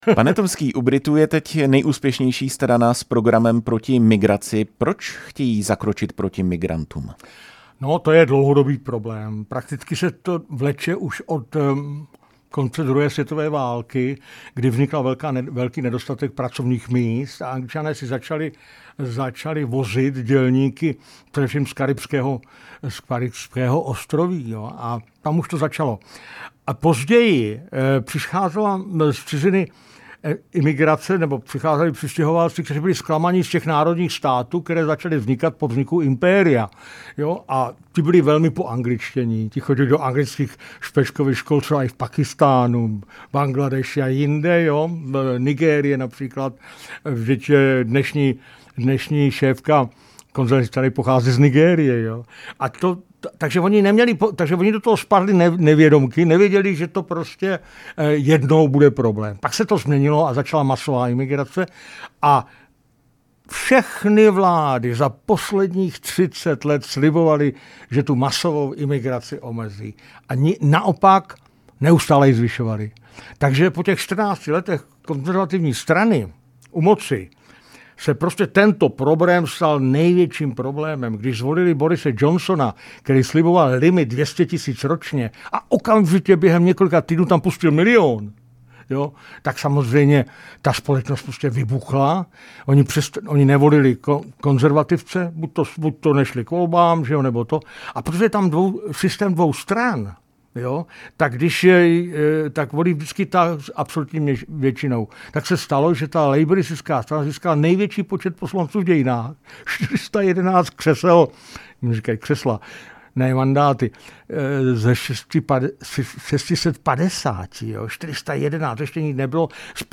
Rozhovor s politologem